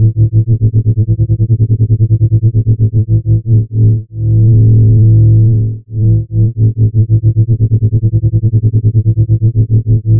AM/FM synthesizer
Gloomy AM/FM sound